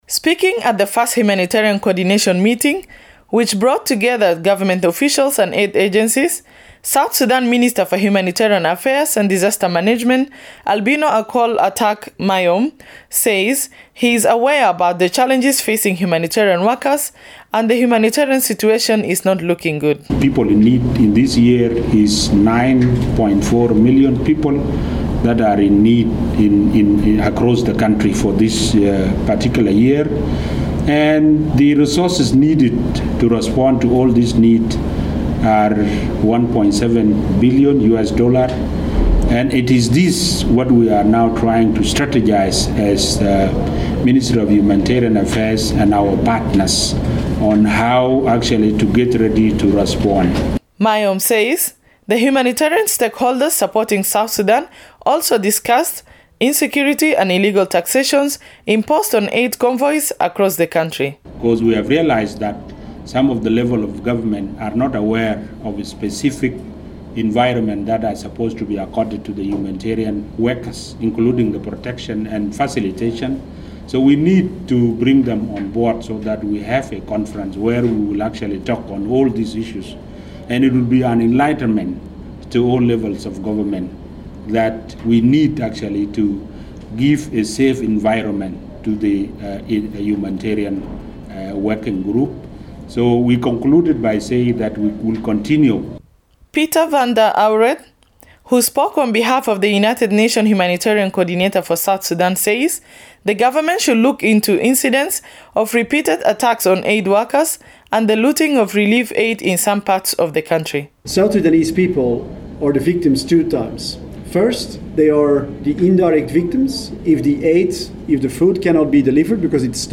reports from Juba